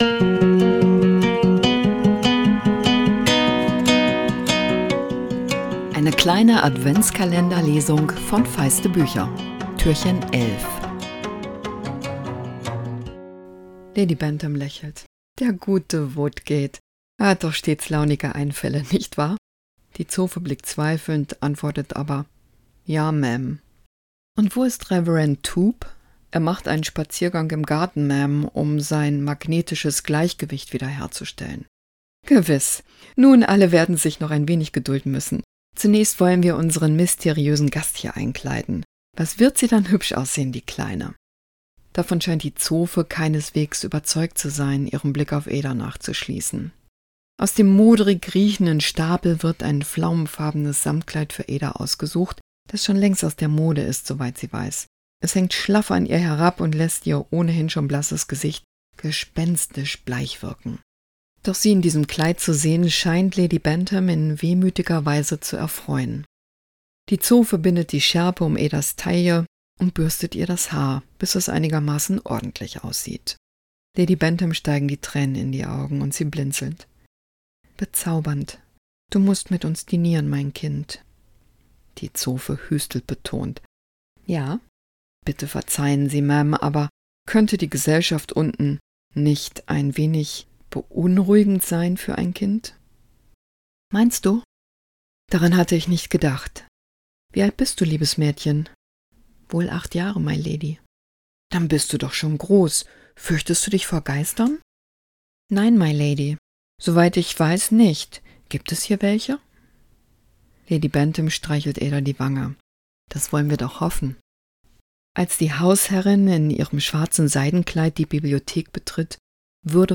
Adventskalender-Lesung 2024! Jess Kidd nimmt euch mit ins Leben des Waisenmädchens Ada Lark, das unfreiwillig Teil eines zwielichtigen Plans wird...